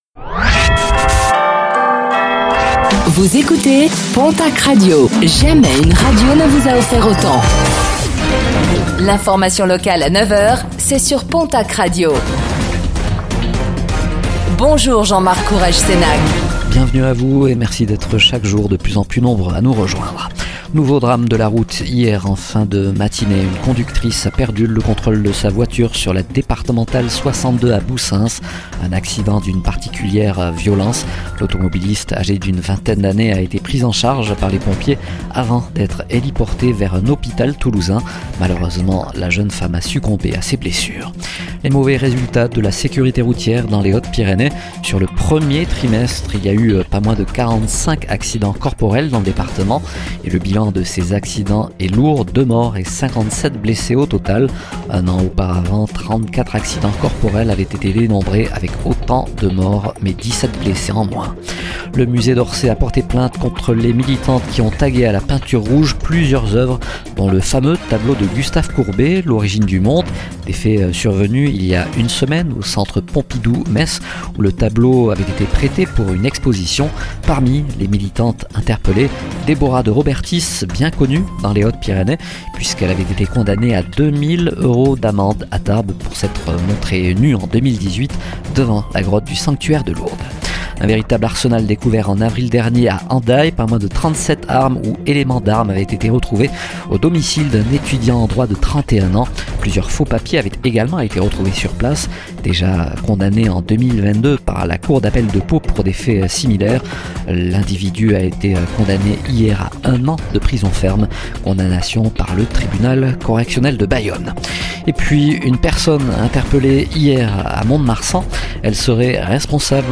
09:05 Écouter le podcast Télécharger le podcast Réécoutez le flash d'information locale de ce mardi 14 mai 2024